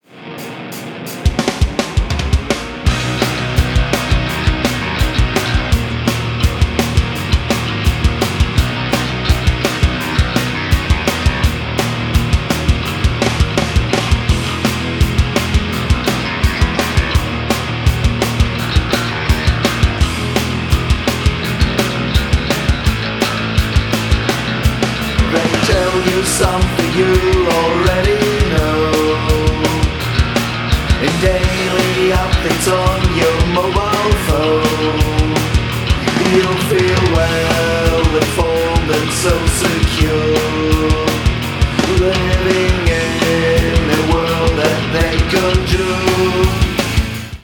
Ihr hört Intro und die erste Strophe: Anhang anzeigen 155190